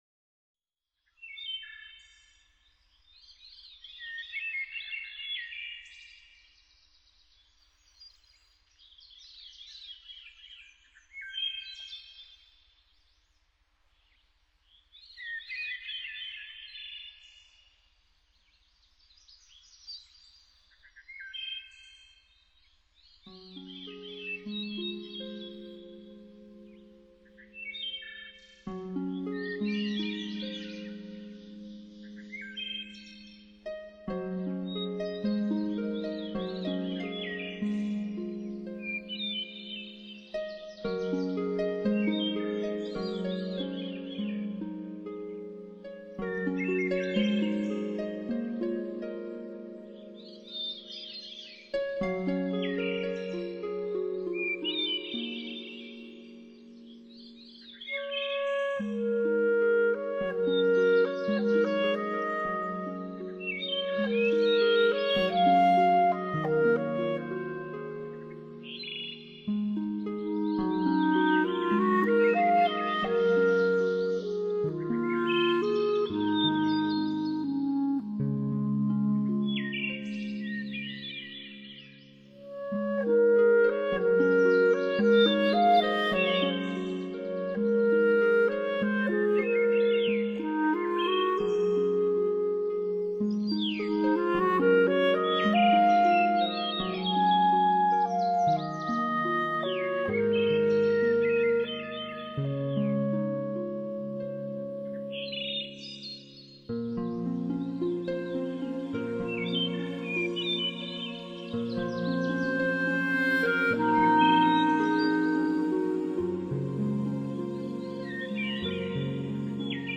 [原创]【配乐散文诗系列】《相思梦》(情感小品文) 继续中。。。